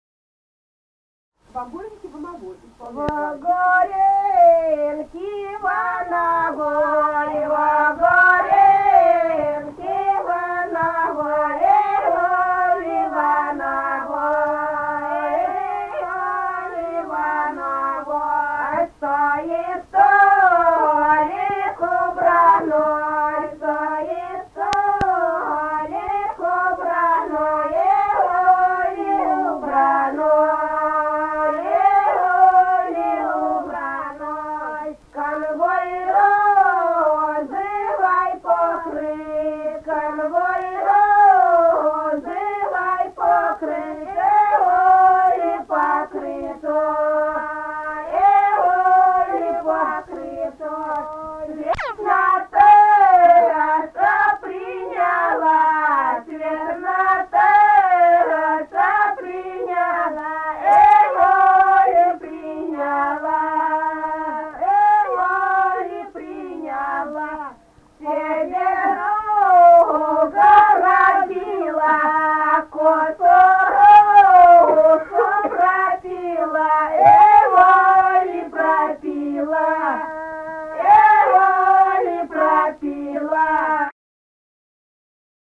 Русские народные песни Владимирской области 15б. Во горенке во новой (свадебная жениху) д. Глебовка Муромского района Владимирской области.